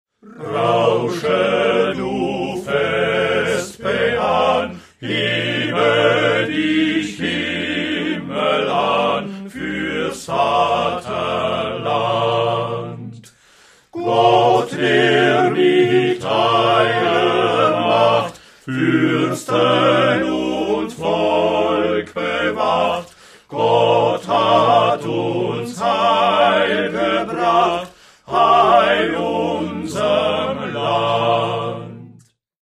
Liedbeispiele ca. 1760-1890
Rohrdorfer Sänger (4, 18)